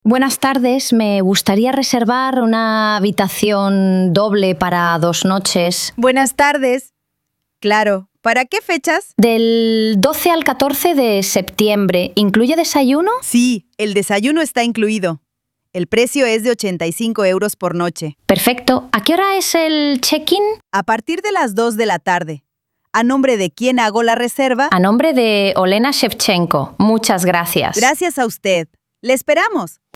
Diálogo · Por teléfono 00:00